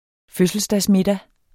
Udtale [ ˈføsəlsdas- ]